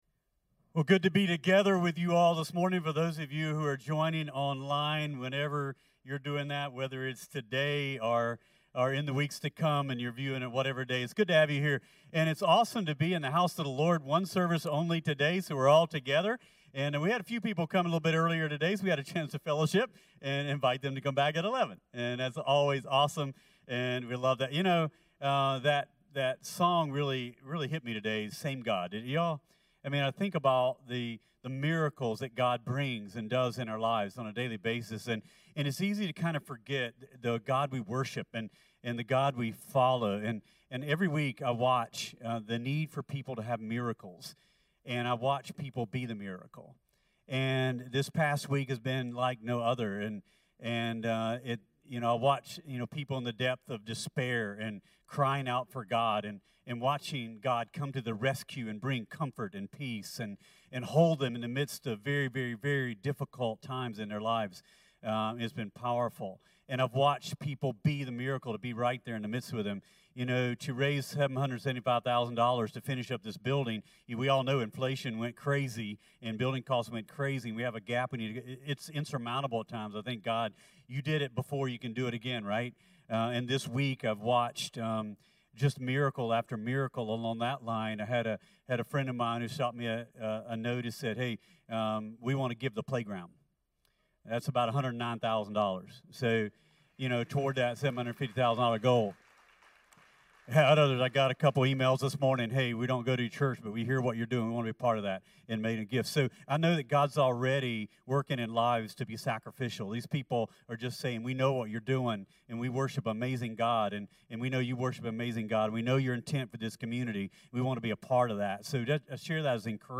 CTK-clipped-sermon.mp3